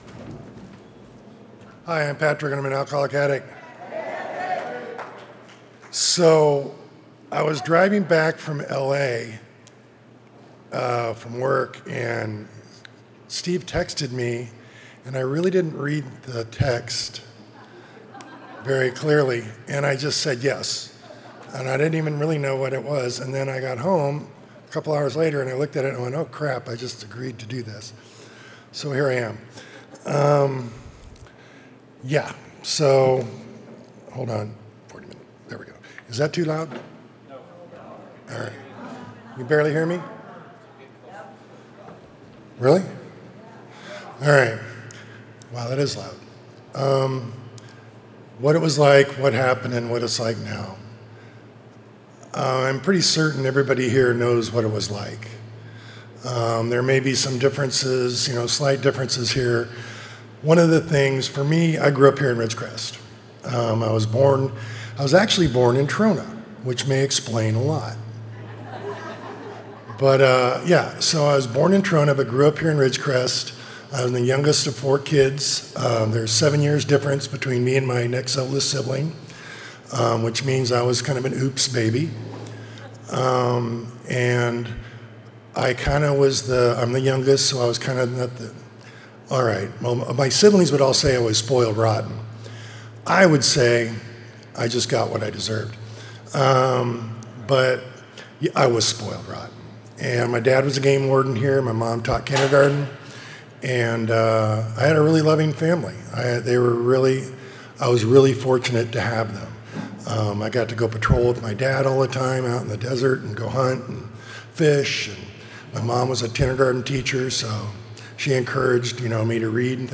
35th Indian Wells Valley Roundup &#8211